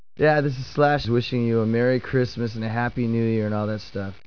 Slash już w świątecznym nastroju, dziś rano przy okazji krótkiego wywiadu powiedział krótkie Merry Christmas do fanów i nie tylko, można odsłuchać